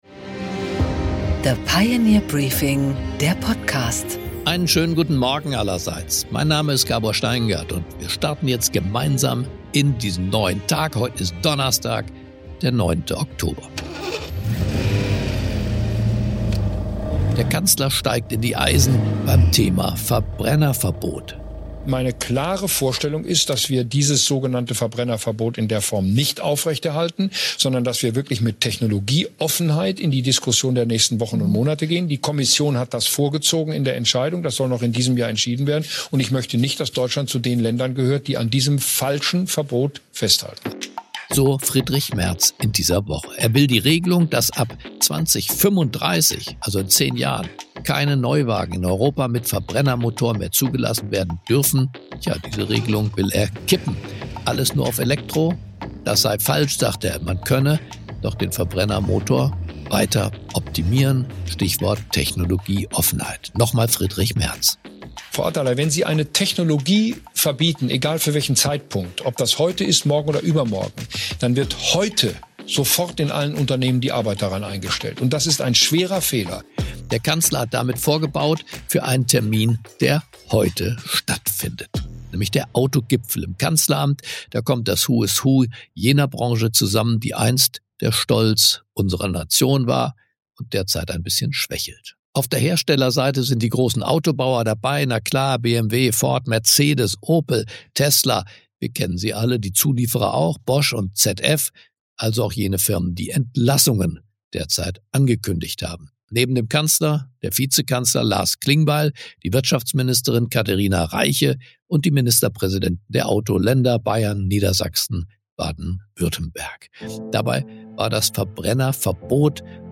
Gabor Steingart präsentiert das Pioneer Briefing